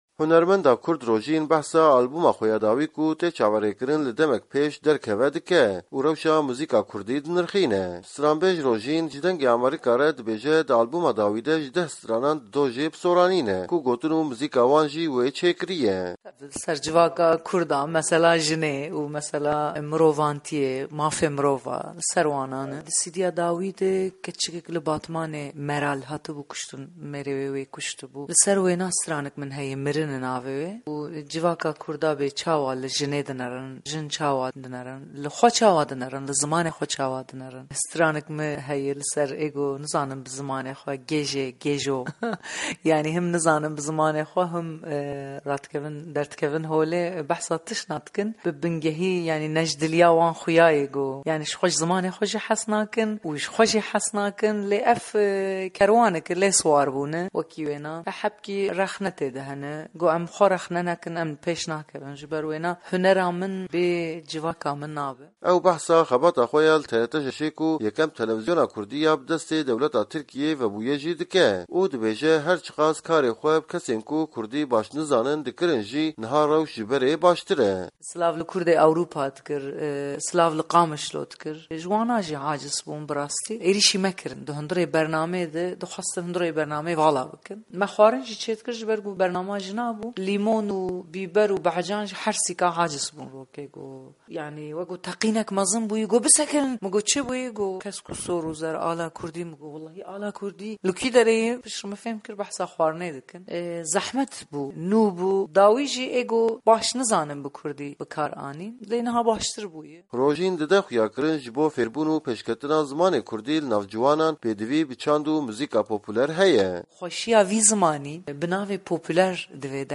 Hevpeyivin digel Rojîn